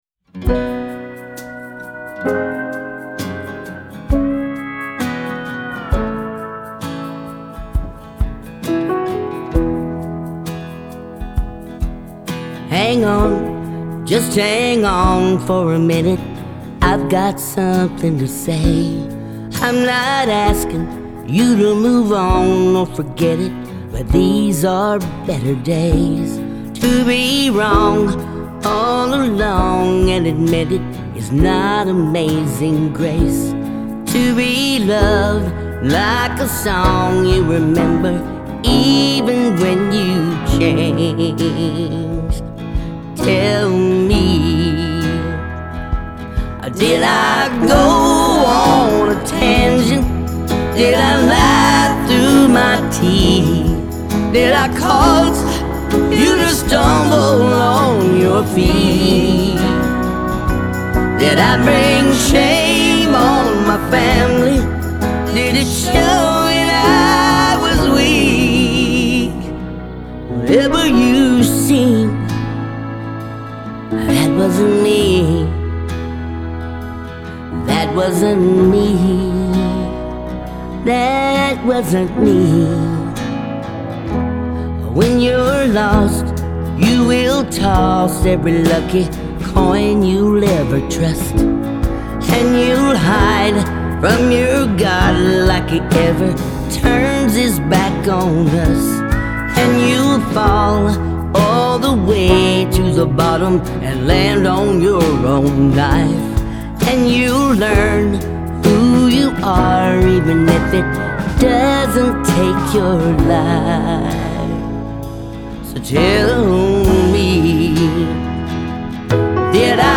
Genre : Country